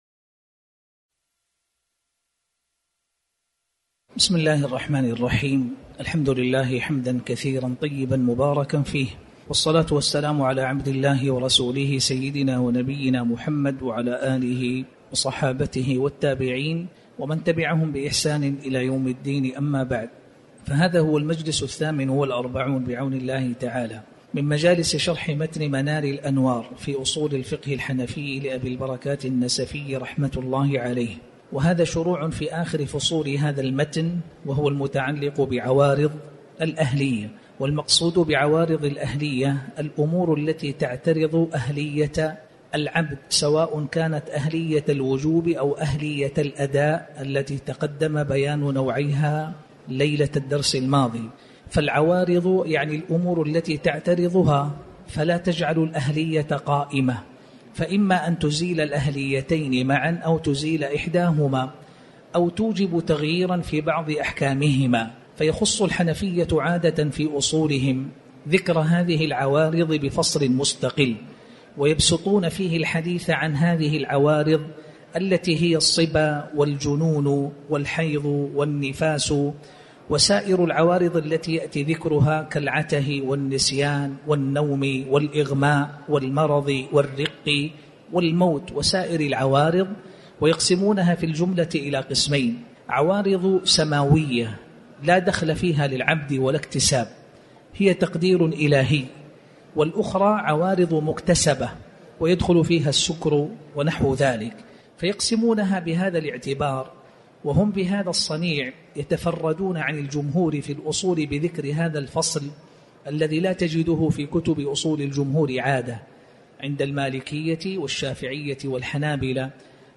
تاريخ النشر ٨ جمادى الآخرة ١٤٤٠ هـ المكان: المسجد الحرام الشيخ